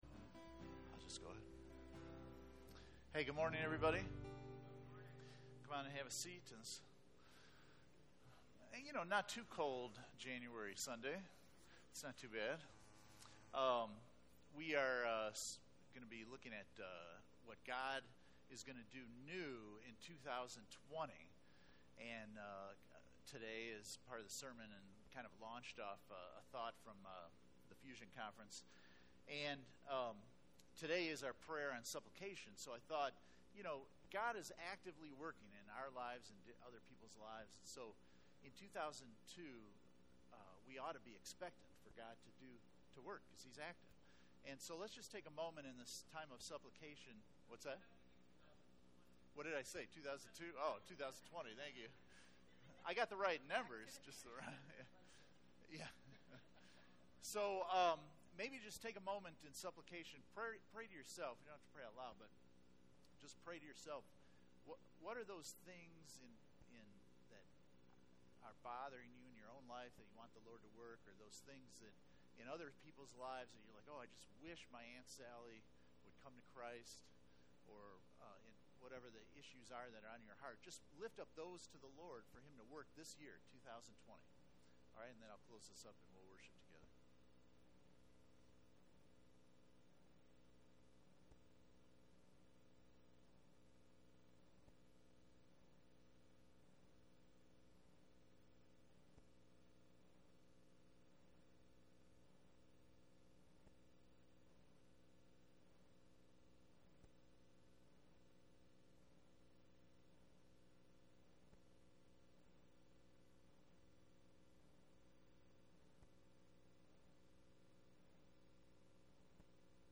Fusion Conference Service Type: Sunday Morning %todo_render% « Fusion Conference 2020 Take Away Pt 2 The Call